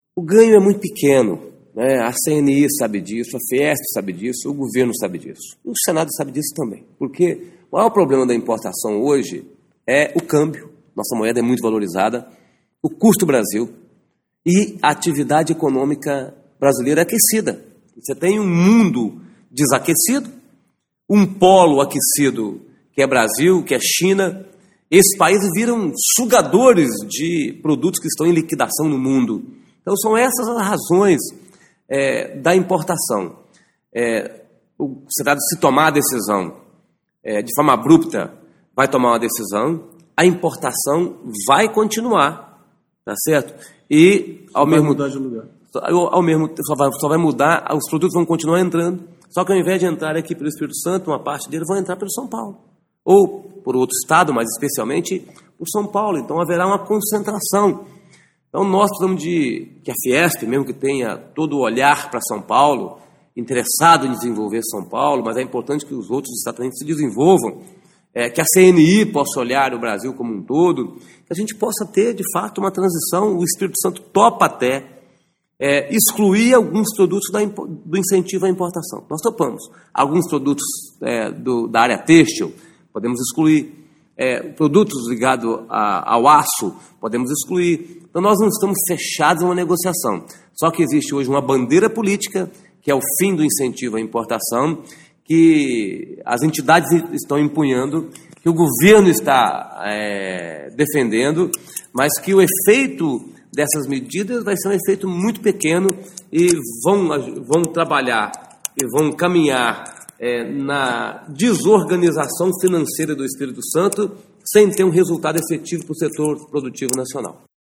entrev_casagrande.mp3